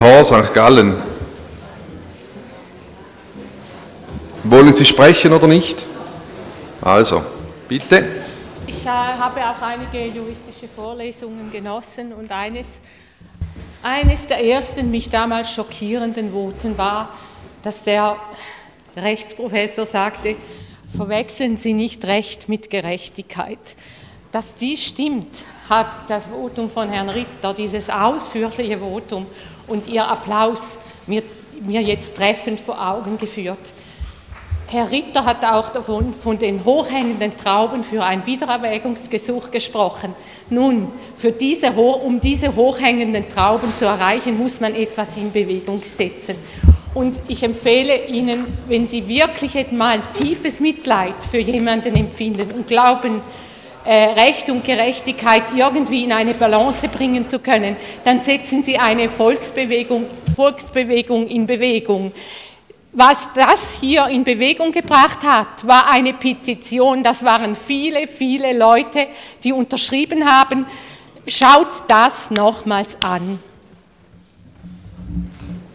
Session des Kantonsrates vom 24. bis 26. November 2014